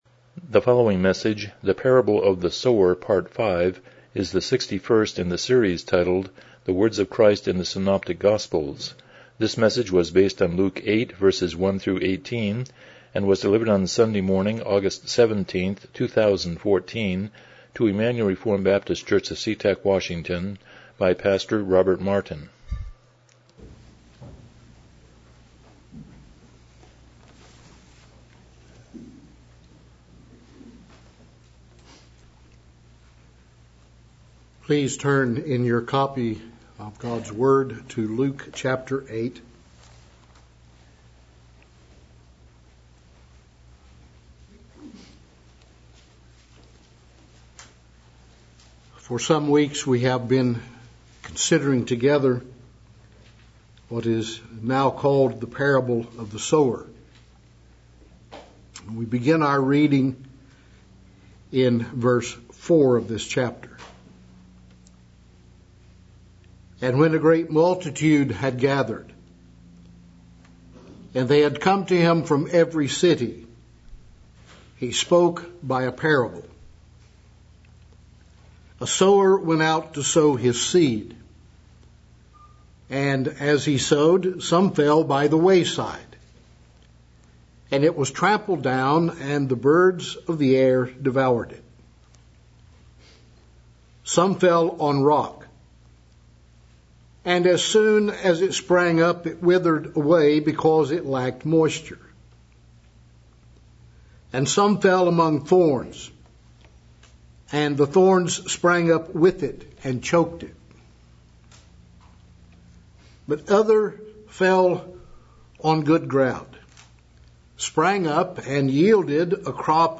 Passage: Luke 8:1-18 Service Type: Morning Worship